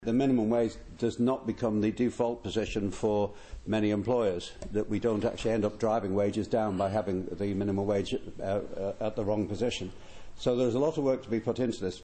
Listen to this audio That's Chief Minister Allan Bell who made the announcement in the House of Keys this morning.